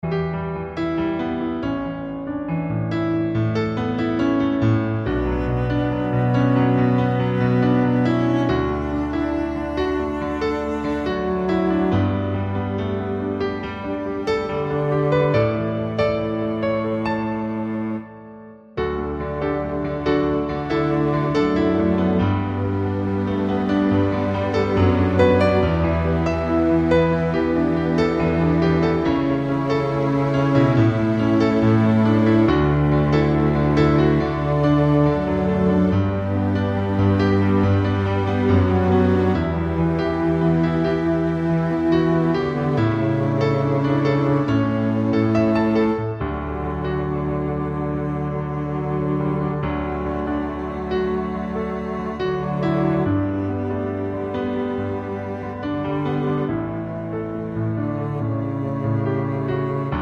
Two Semitones Up For Male